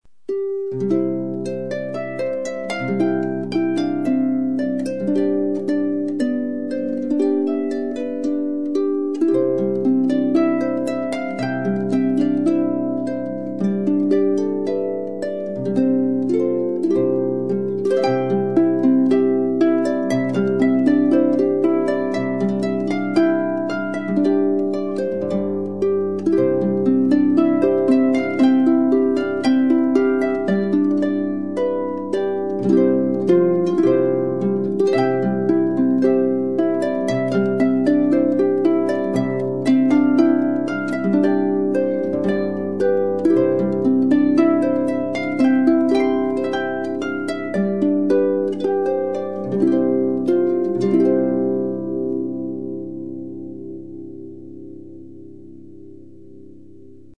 Seattle-Harpist-3-SimpleGifts.mp3